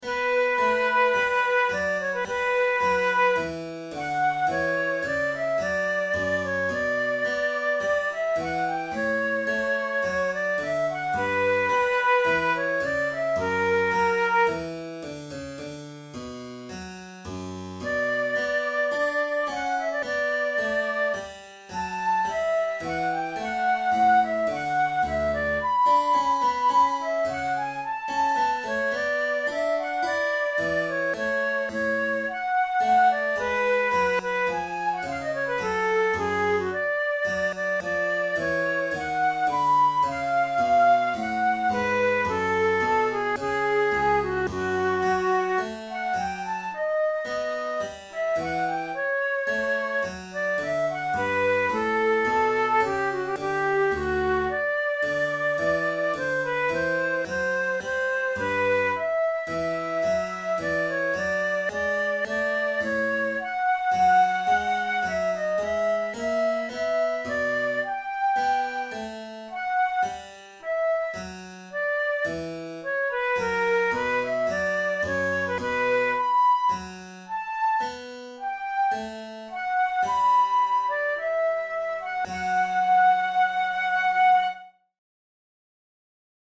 This is the opening movement of a sonata in B minor for flute and harpsichord, sometimes referred to as HWV 367b, by George Frideric Handel.
Categories: Baroque Sonatas Written for Flute Difficulty: intermediate